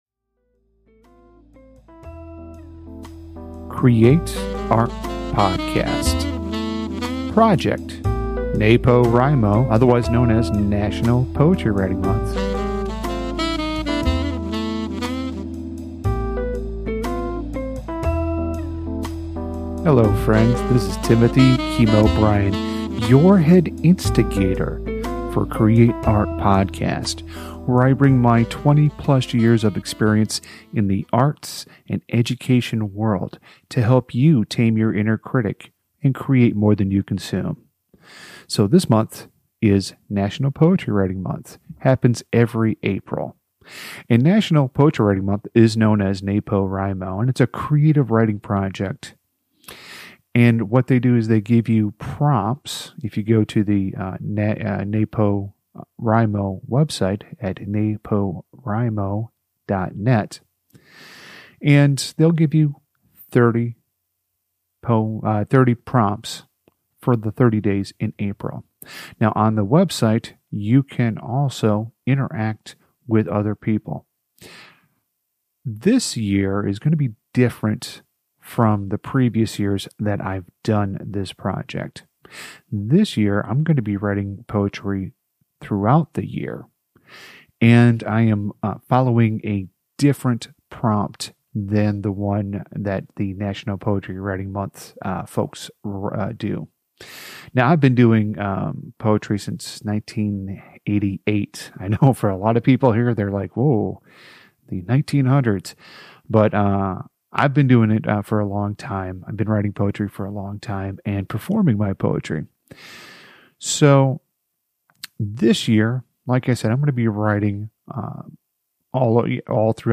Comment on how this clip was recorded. Some of the podcast episodes you will hear will be a live recording of me reading the poem to a live audience, other times I will be reading it in the comfort of my home studio.